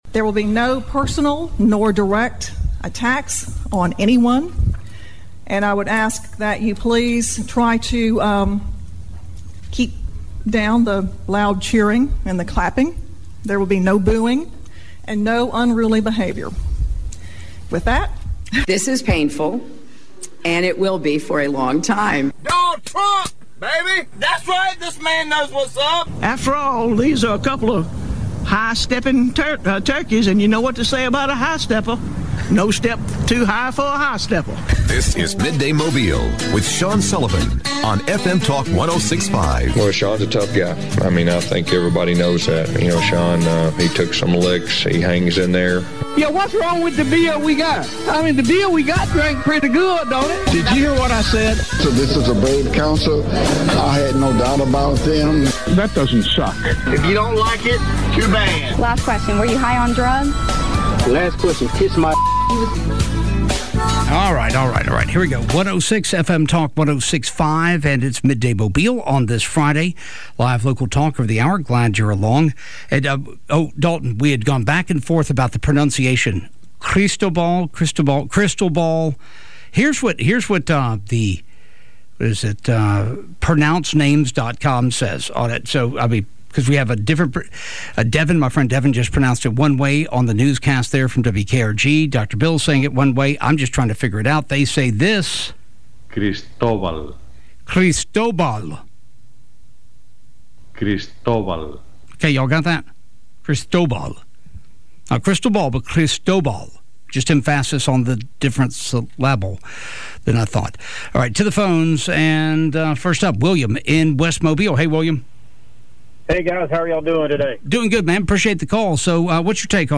talks with callers about protests and the removal of the Admiral Semmes statue in Mobile.